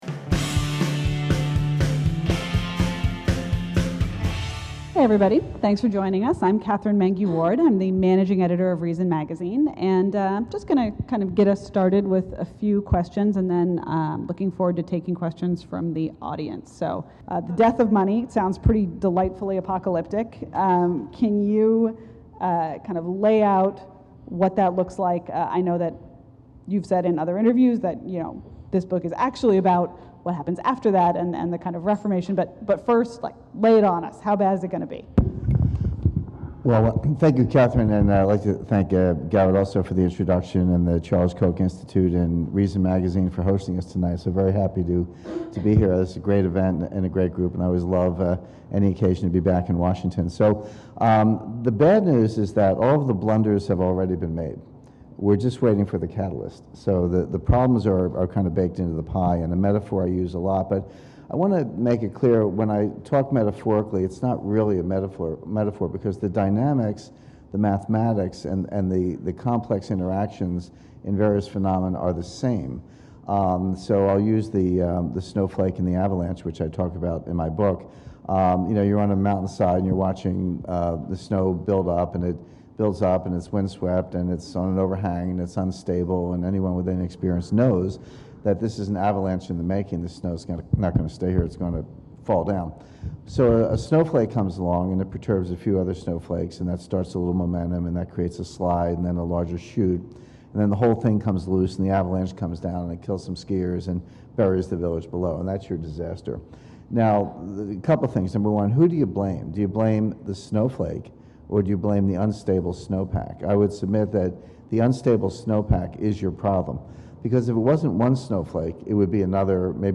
The Death of Money: Q&A With James Rickards